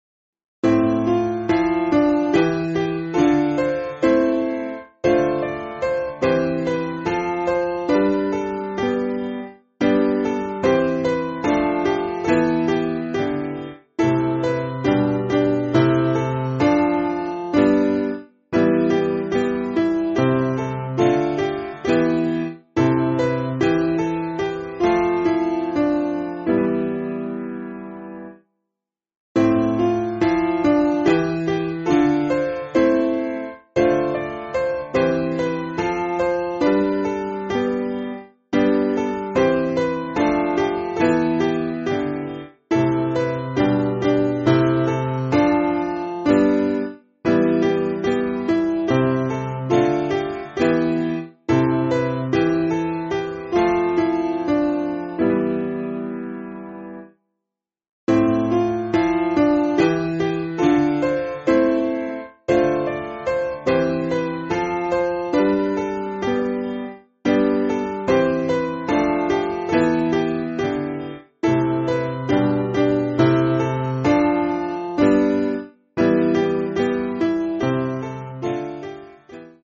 Simple Piano
with Amen